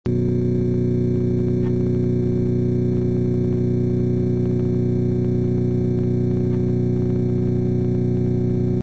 I am getting these "pops" when at low idle with the BF1.
So for instance in this sound clip, I parked next to the side wall on the oval and it would start popping pretty loud.
bf1_pops.mp3 - 60.4 KB - 774 views